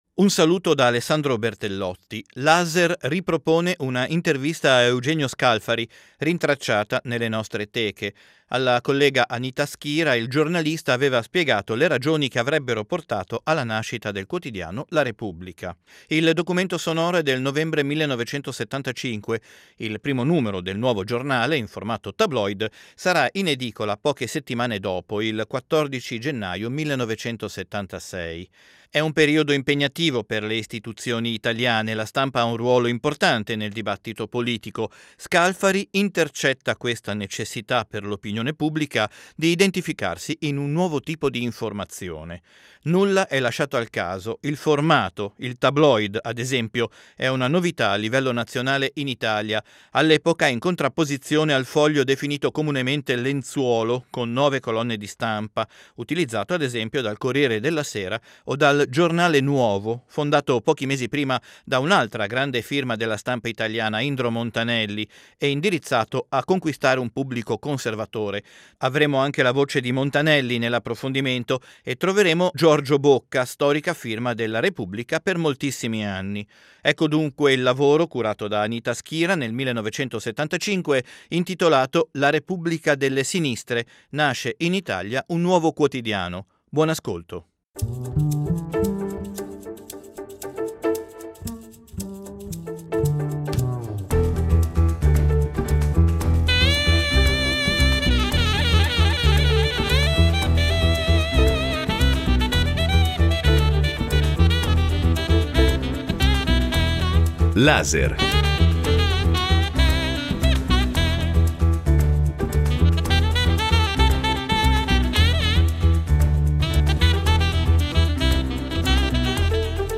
Intervista a Eugenio Scalfari